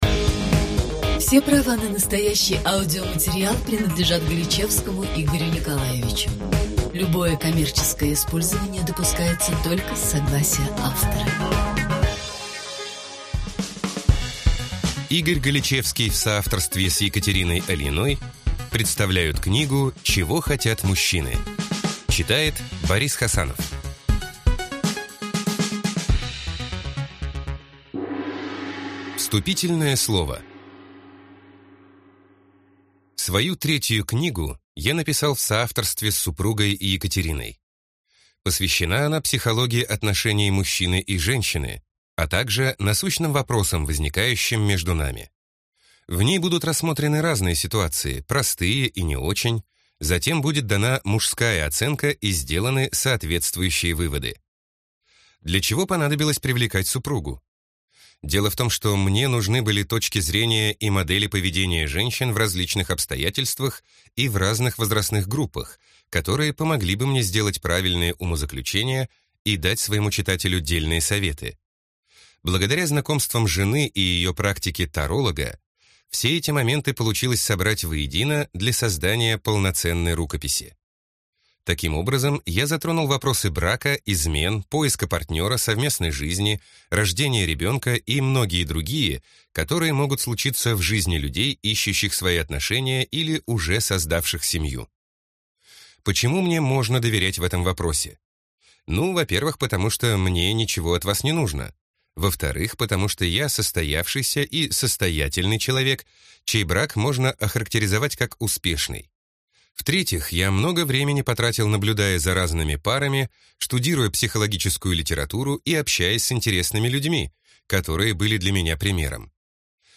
Аудиокнига Чего хотят мужчины | Библиотека аудиокниг